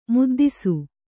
♪ muddisu